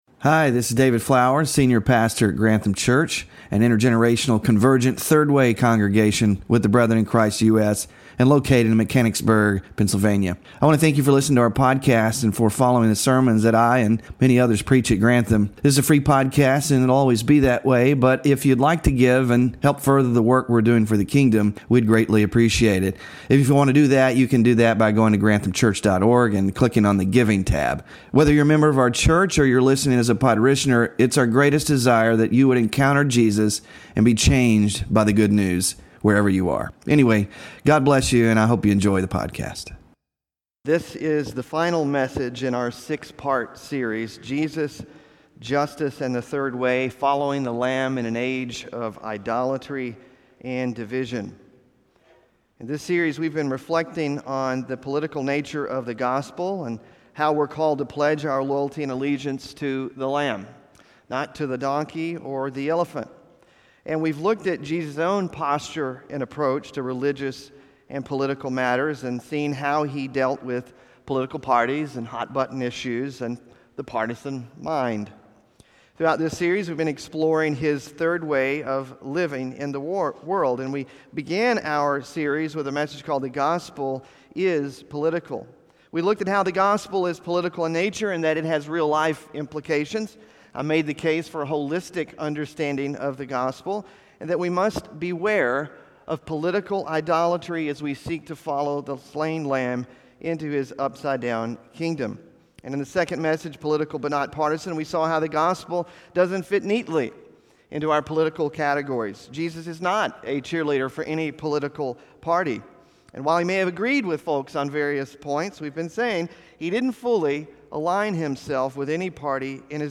QUESTIONS & OBJECTIONS: BEING FAITHFUL TO THE LAMB SERMON SLIDES (6th OF 6 IN SERIES) SMALL GROUP DISCUSSION QUESTIONS (11-10-24) BULLETIN (11-10-24)